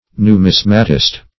Numismatist \Nu*mis"ma*tist\, n.